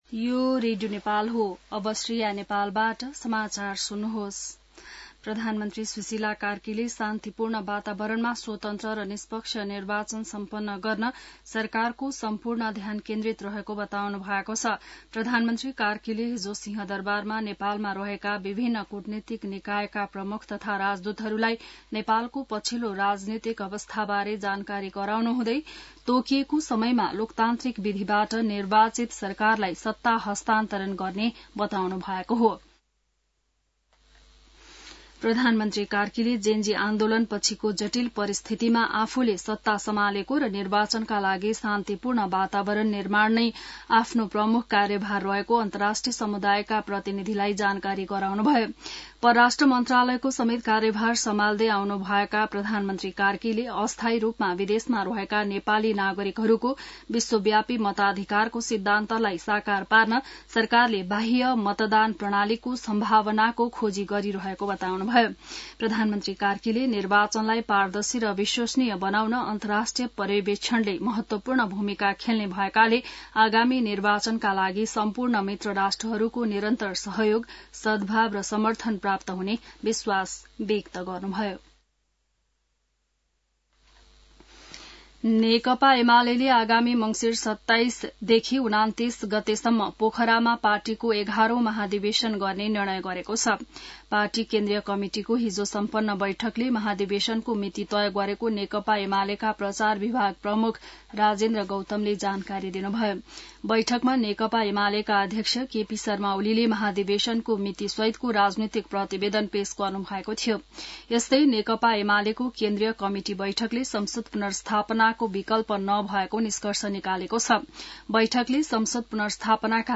बिहान ६ बजेको नेपाली समाचार : १ कार्तिक , २०८२